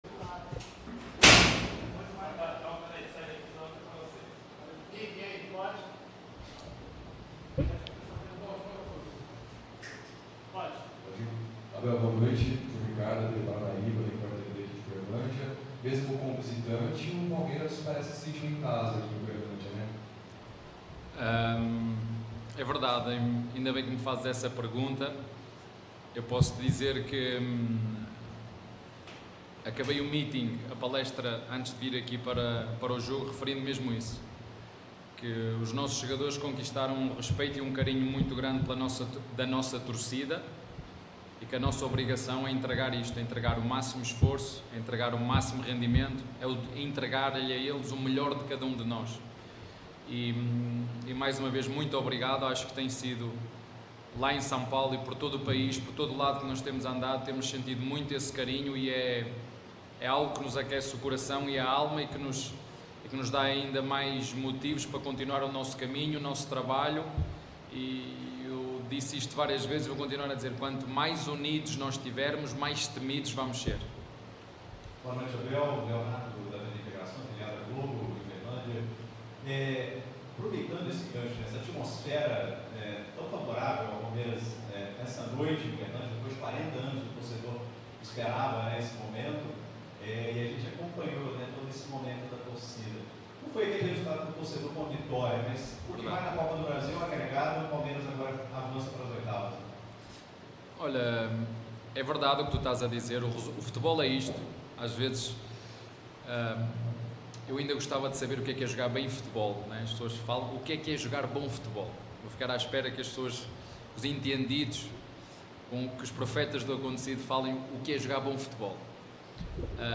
COLETIVA-ABEL-FERREIRA-_-TOMBENSE-X-PALMEIRAS-_-COPA-DO-BRASIL-2023.mp3